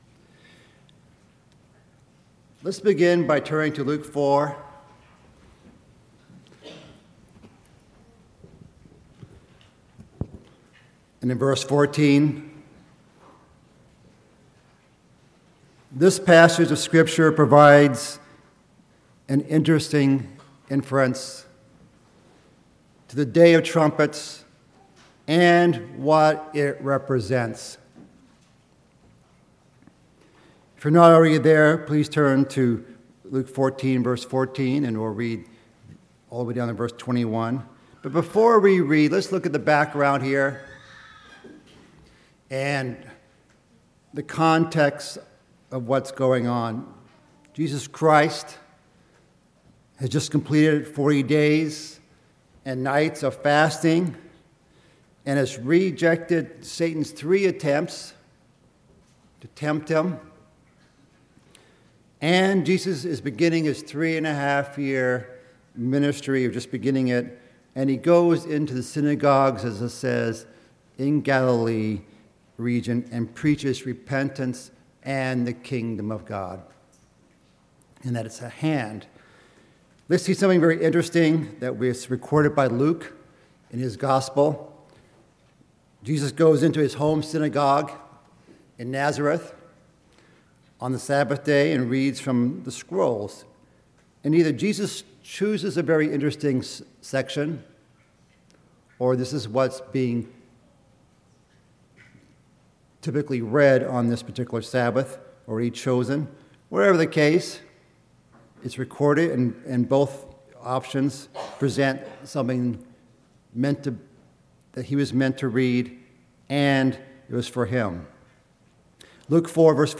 Sermons
Given in Chicago, IL Northwest Indiana